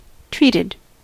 Ääntäminen
Ääntäminen US Haettu sana löytyi näillä lähdekielillä: englanti Käännöksiä ei löytynyt valitulle kohdekielelle. Treated on sanan treat partisiipin perfekti.